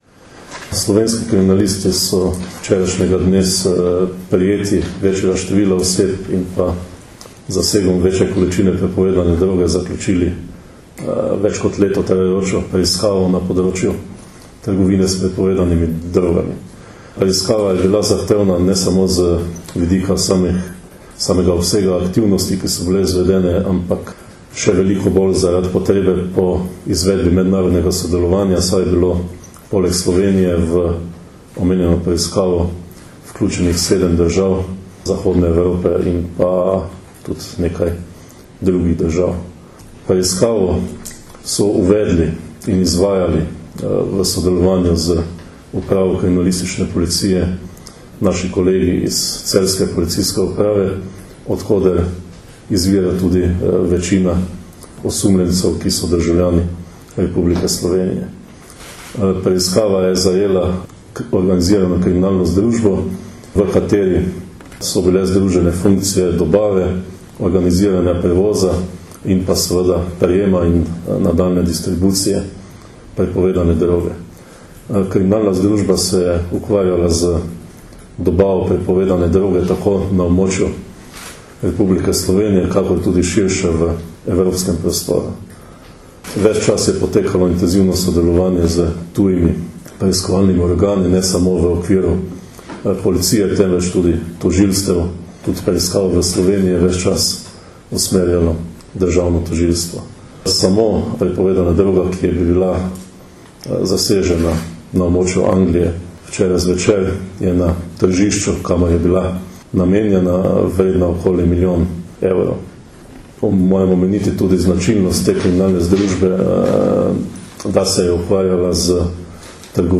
Zvočni posnetek izjave direktorja Uprave kriminalistične policije Marjana Fanka (mp3)